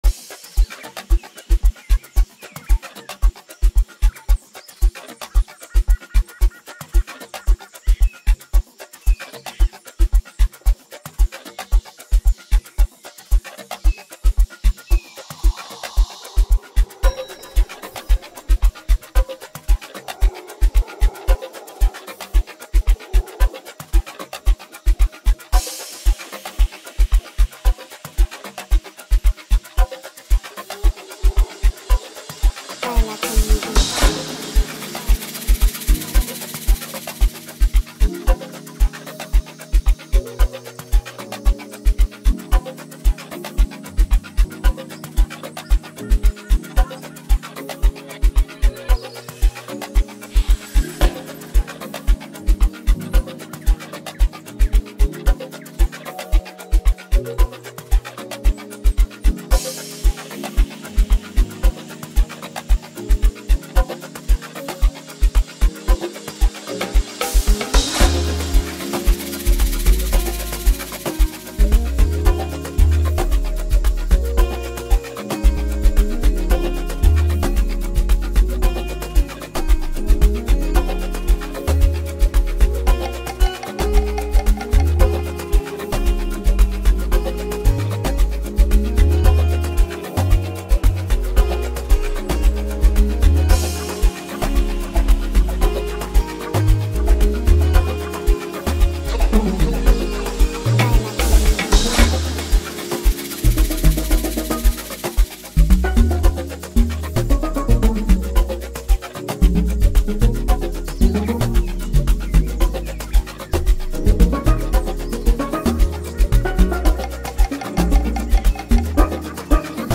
captivating melodies and heartfelt lyrics
smooth vocals
catchy rhythm